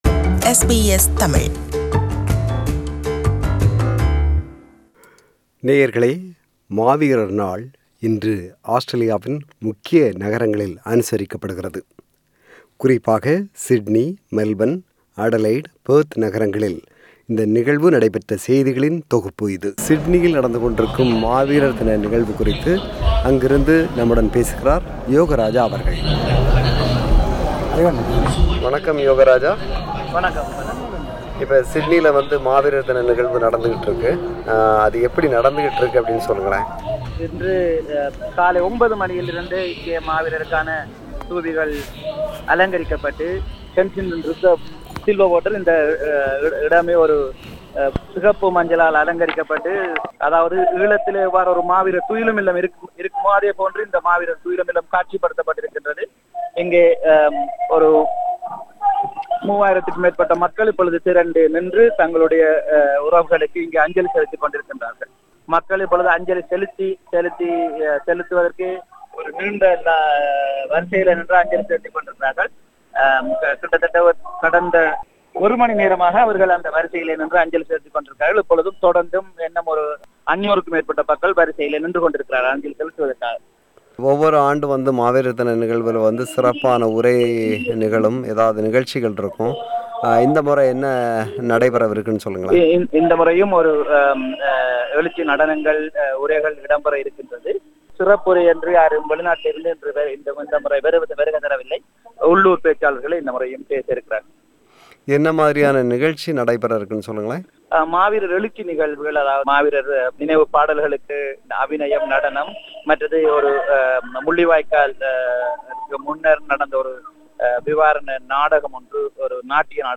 Maaveerar Naal is observed all over the world to remember the deaths of Tamil people died during the civil war in Sri Lanka since 1982. This is a compilation of reports from Sydney, Adelaide and Perth where Maaveerar Naal is observed today (27 Nov 2019).